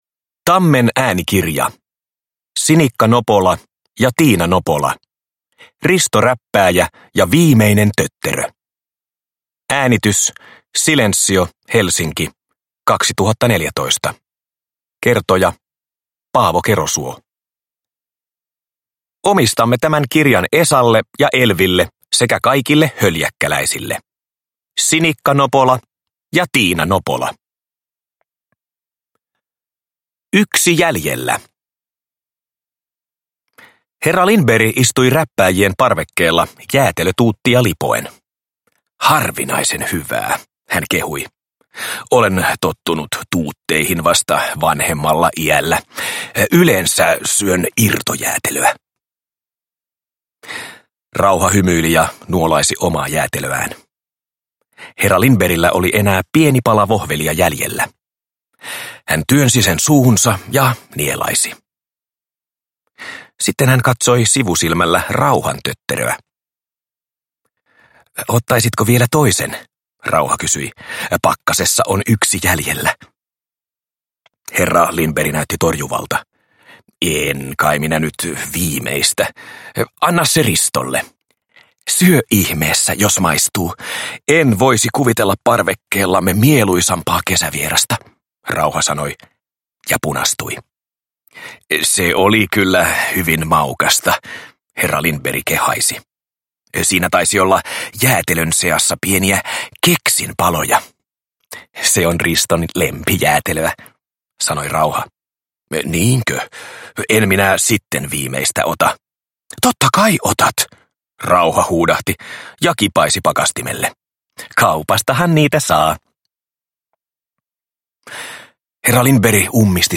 Risto Räppääjä ja viimeinen tötterö – Ljudbok